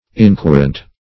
Search Result for " inquirent" : The Collaborative International Dictionary of English v.0.48: Inquirent \In*quir"ent\, a. [L. inquirens, p. pr.]
inquirent.mp3